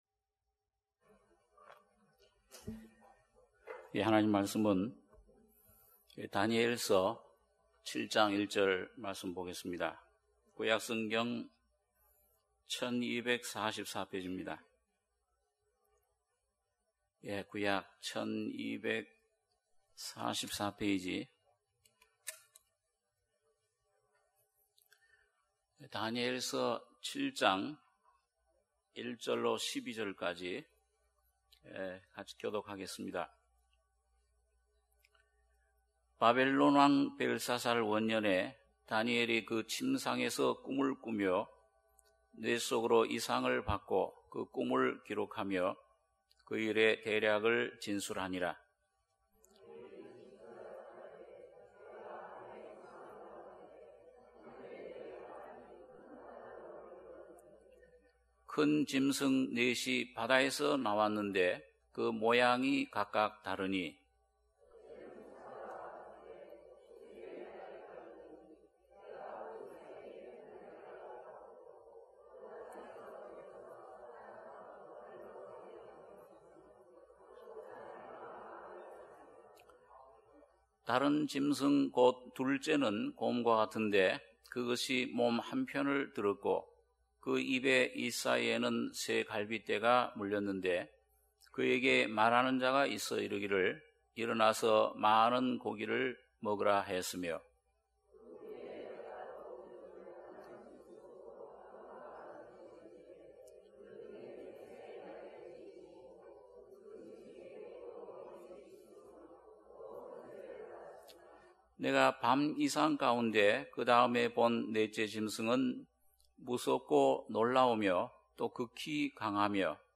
주일예배 - 다니엘 7장 1절-12절